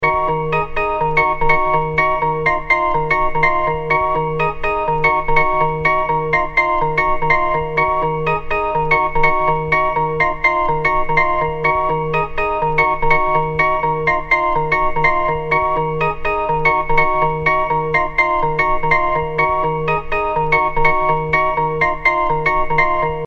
• Category Old Skool